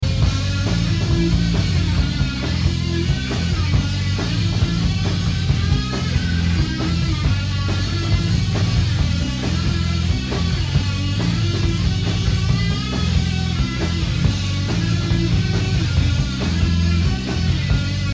Qualité audio: 8.5/10 Il y a 4 sources audience disponibles.